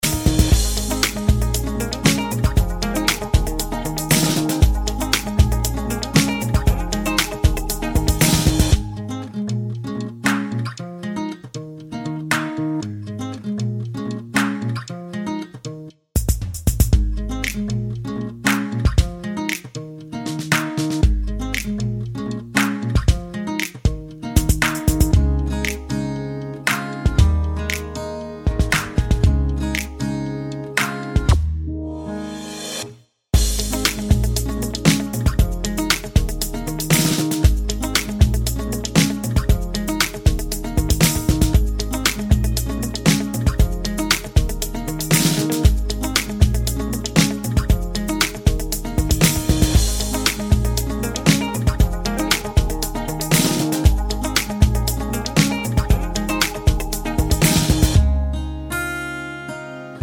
no Backing Vocals Pop (2020s) 2:31 Buy £1.50